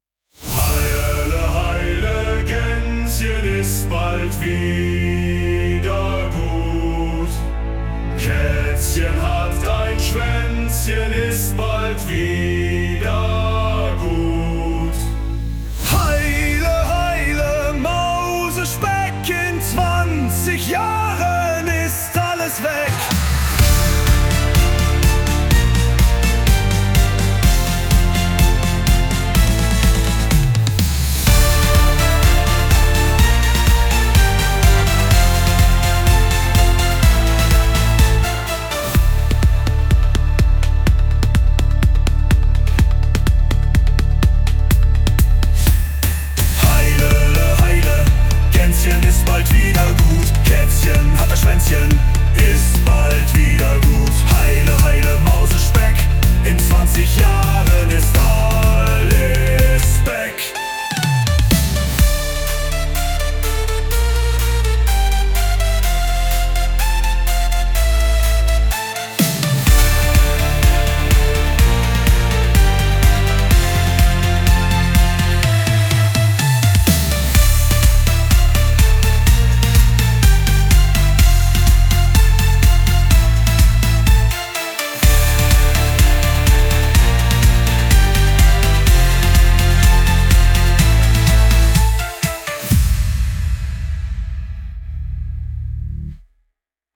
Hörprobe: Heile heile Gänzchen als Metal Power Rock